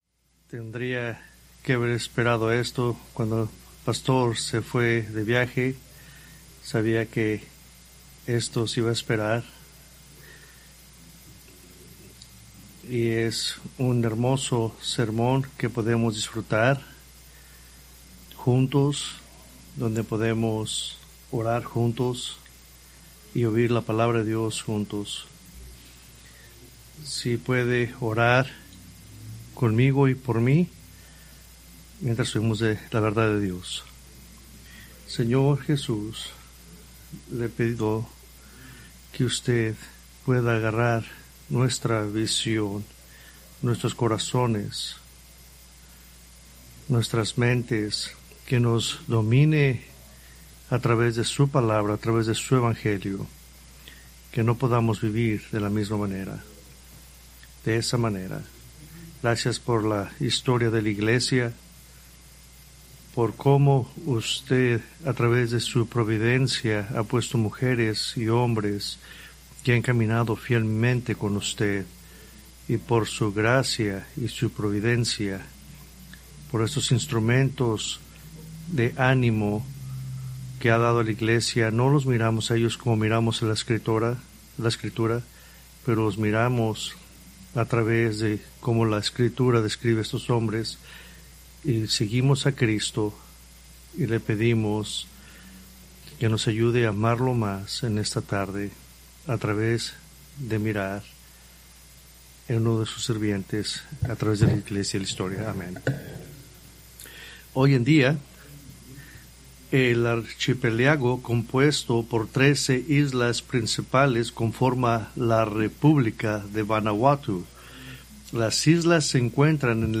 Preached January 18, 2026 from Escrituras seleccionadas